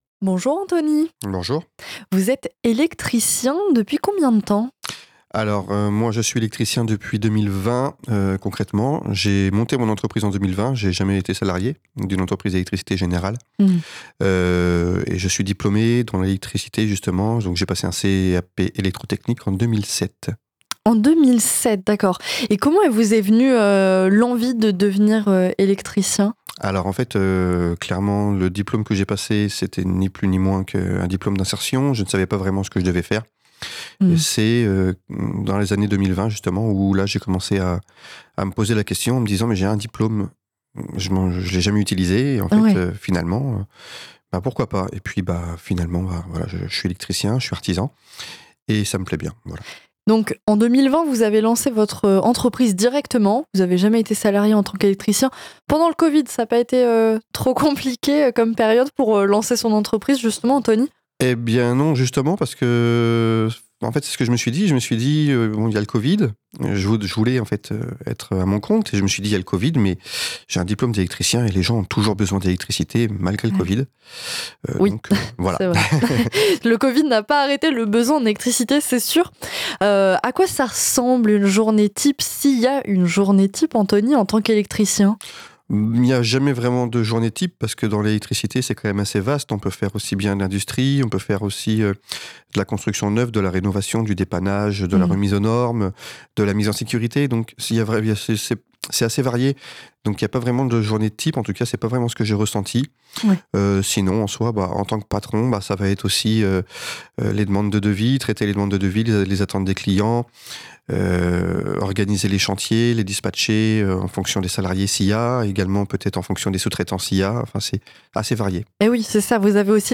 Une interview pour mieux comprendre ce qui se cache derrière un simple interrupteur.